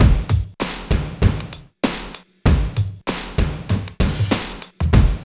45 DRUM LP-L.wav